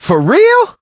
brock_hurt_01.ogg